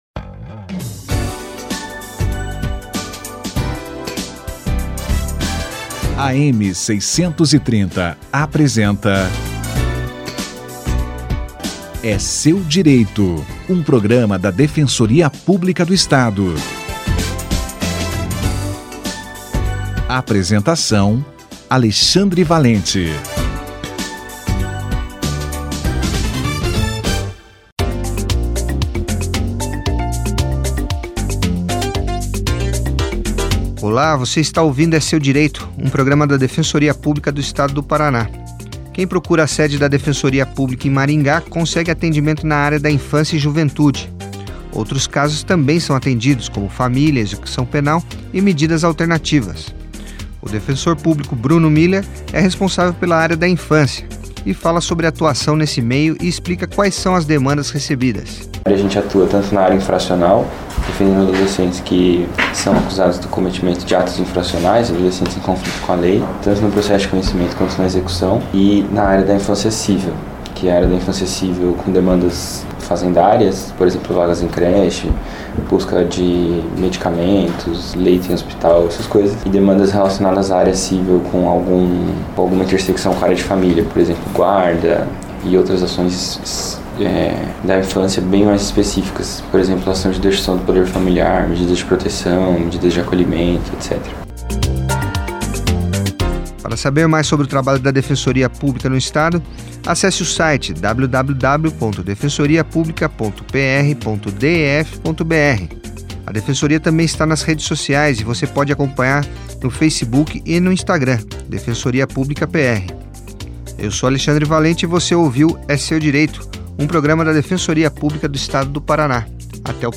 24/01/2019 - Defensor Público de Maringá fala sobre demandas na área da infância